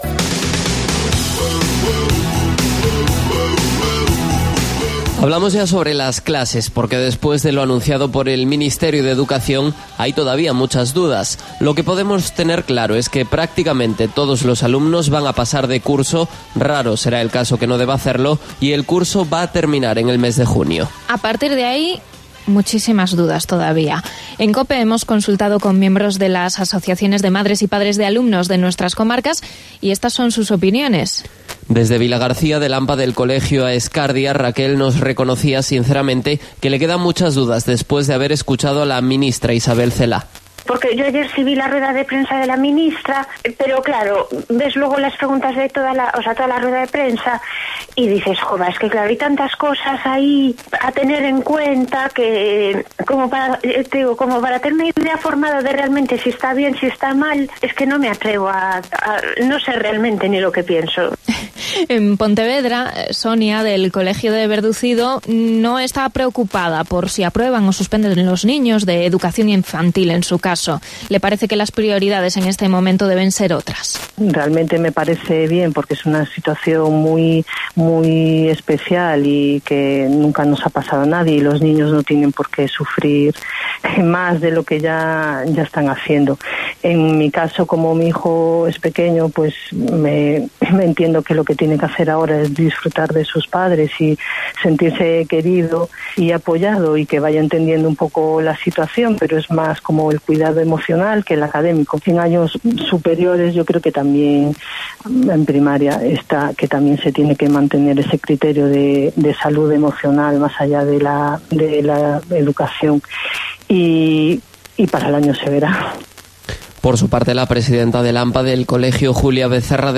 Opiniones de padres y madres de alumnos de Pontevedra y O Salnés